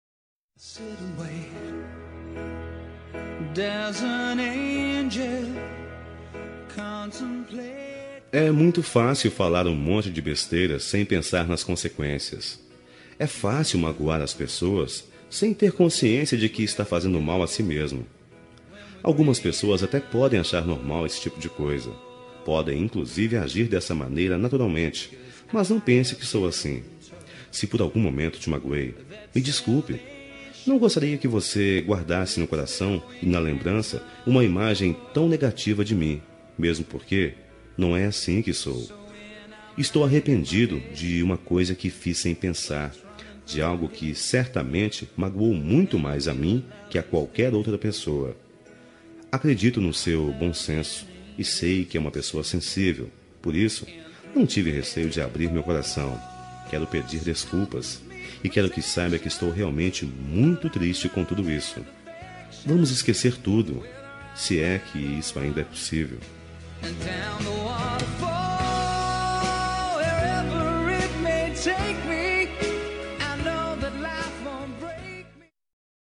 Telemensagem Desculpas – Voz Masculina – Cód: 8070